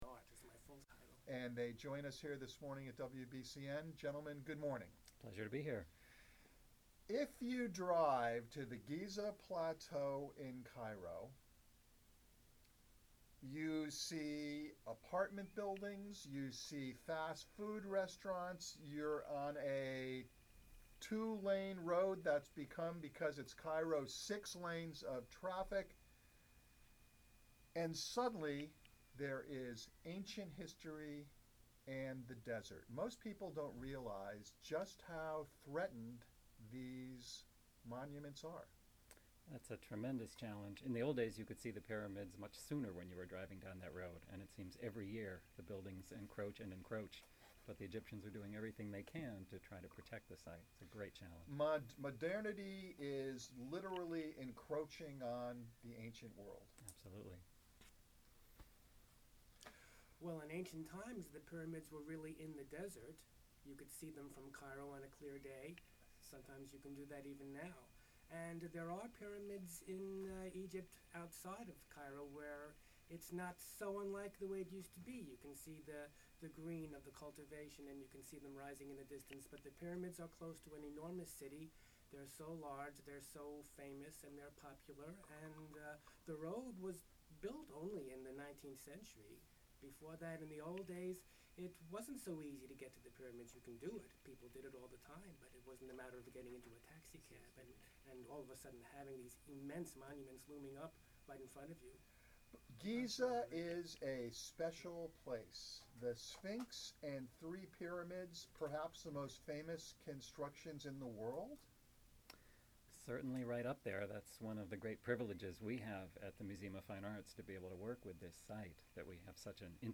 FOR IMMEDIATE RELEASE 7/29/2007: GIZA ARCHIVES PROJECT RADIO INTERVIEW ON BOSTON RADIO STATION WBCN (104.1 FM)